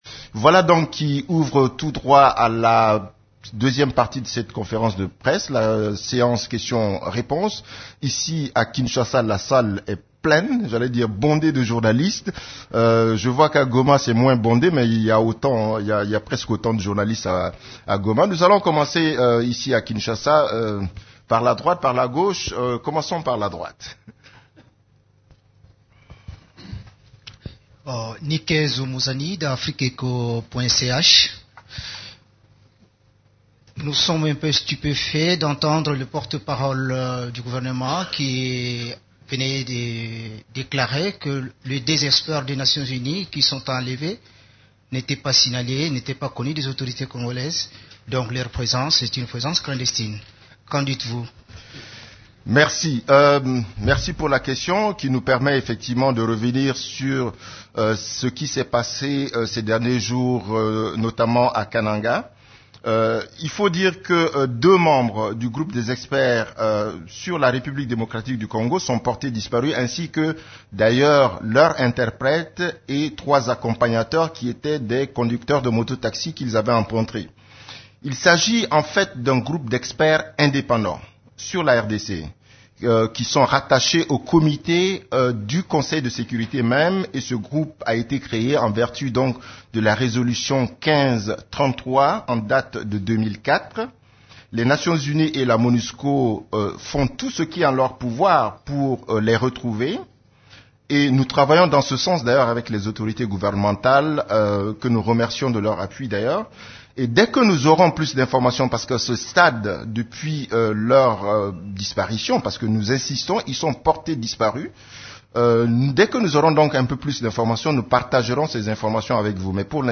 Conférence de presse du 15 mars 2017
La conférence de presse hebdomadaire des Nations unies du mercredi 15 février à Kinshasa a porté sur la situation sur les activités des composantes de la MONUSCO, des activités de l’Equipe-pays ainsi que de la situation militaire à travers la RDC.